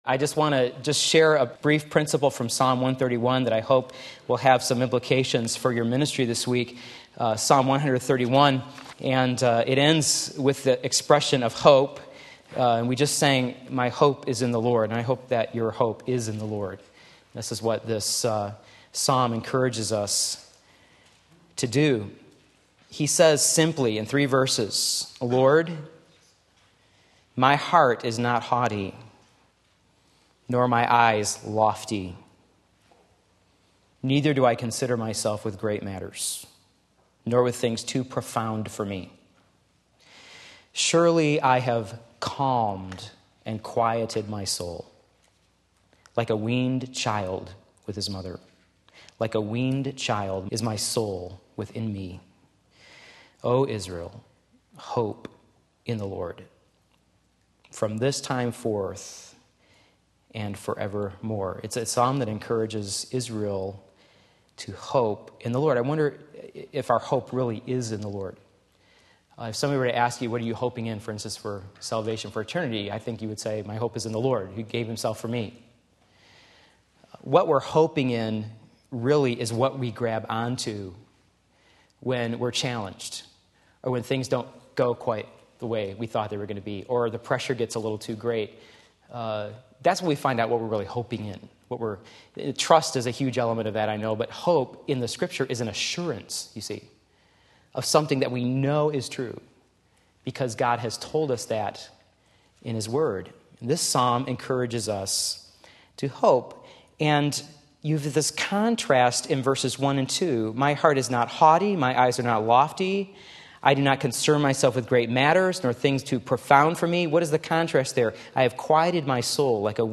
Sermon Link
Song of the Soul at Rest Psalm 131 Sunday Afternoon Service